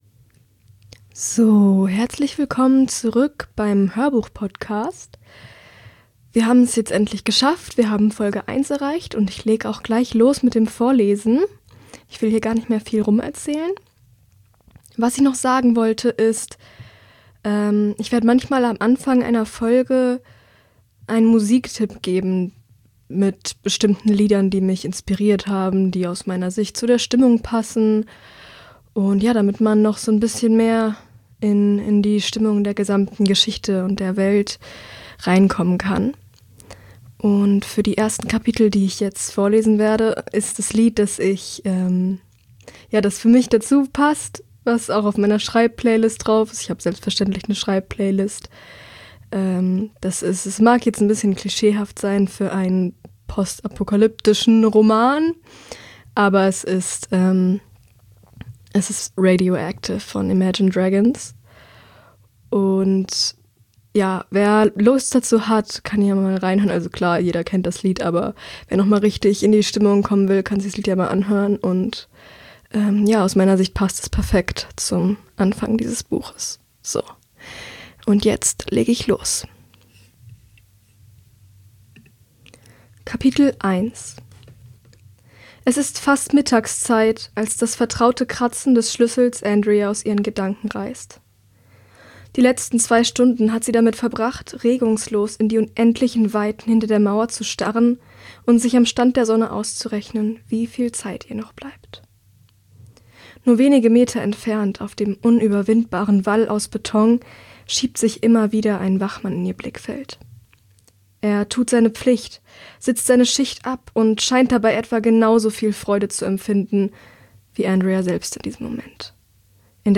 Immuna X - Der Hörbuchpodcast